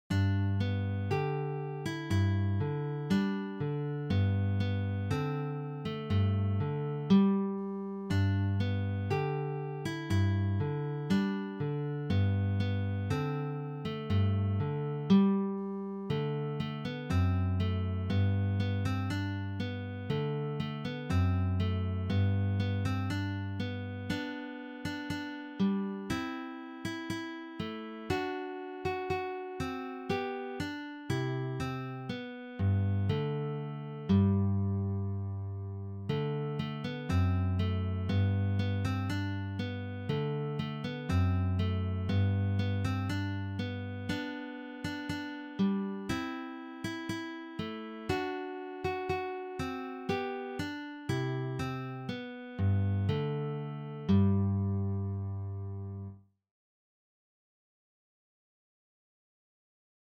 Für Gitarre Solo
Geistliche Musik
Gitarre (1)